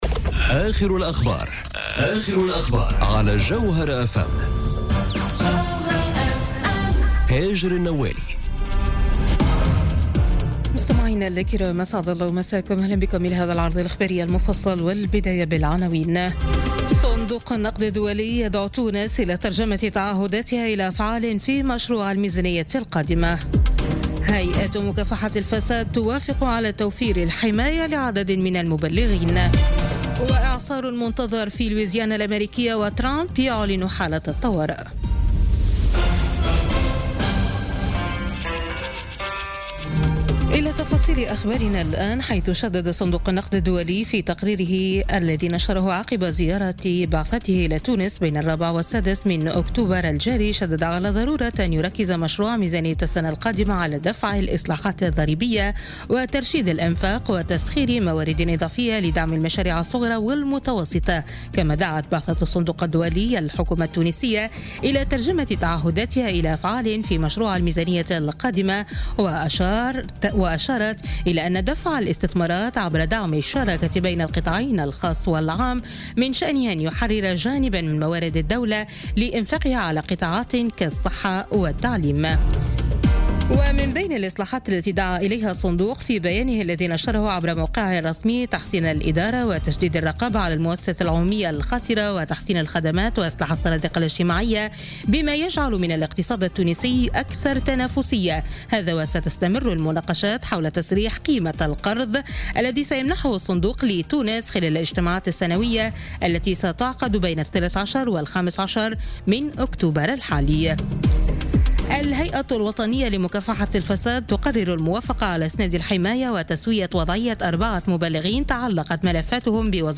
نشرة أخبار منتصف الليل ليوم الأحد 8 أكتوبر 2017